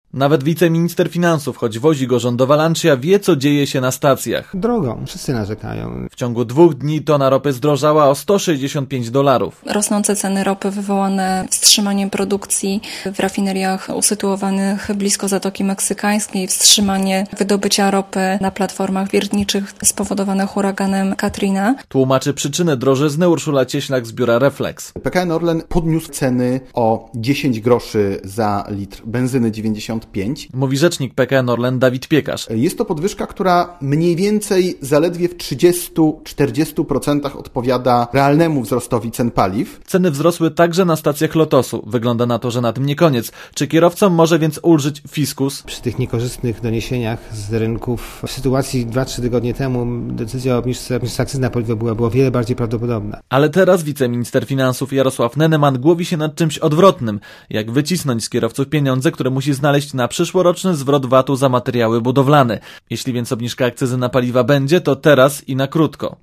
Jesteśmy blisko podjęcia decyzji, ale w grę wchodzi tylko kilka groszy na litrze - mówi wiceminister finansów Jarosław Neneman.